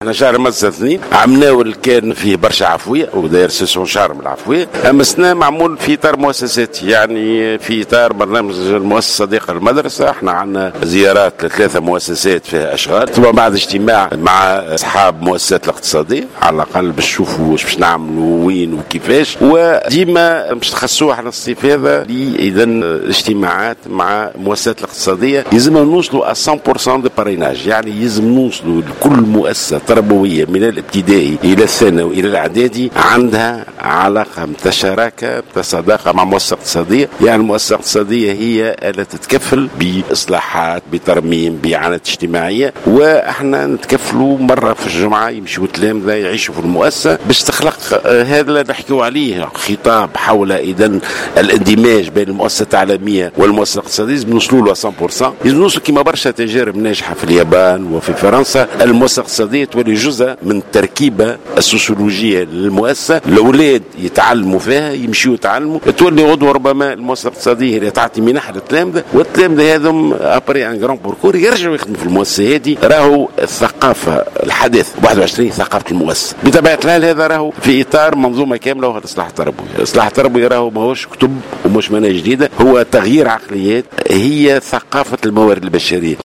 أكد وزير التربية ناجي جلول في تصريح لمراسل الجوهرة" اف ام" على هامش زيارة الى عدد من المؤسسات التربوية بصفاقس على هامش انطلاق شهر المدرسة 2 أن النسخة الاولى من شهر المدرسة بدأت في إطار عفوي العام الماضي مؤكدا أن هذا العام أصبح في إطار المؤسسة الصديقة للمدرسة.